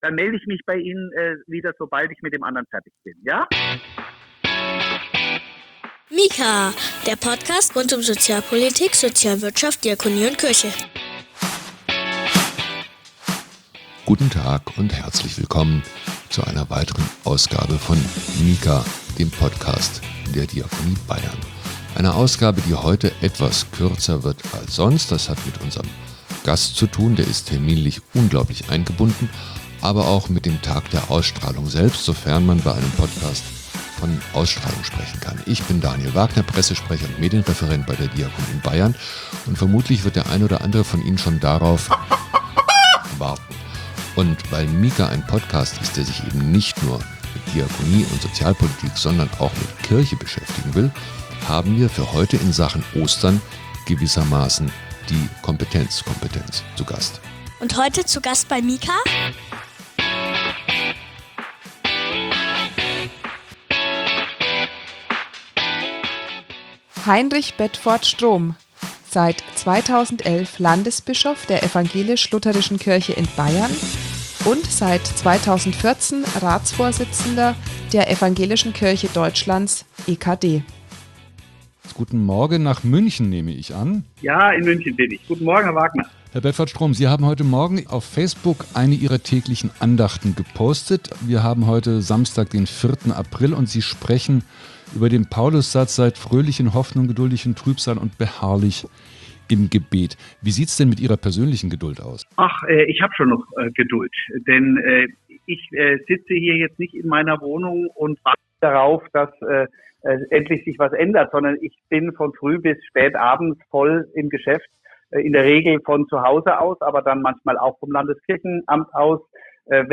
Beschreibung vor 6 Jahren Er ist ein gefragter Mann in diesen Zeiten, und dennoch hat der Landesbischof der ELKB und Ratsvorsitzender der EKD, Heinrich Bedford-Strohm, Zeit genommen, um in MIKA über die Folgen von Corona für die Kirche und über die Seenotrettung zu sprechen. Und Zeit für einen kleinen Ostergruß an die Hörerinnen und Hörer von MIKA war auch noch.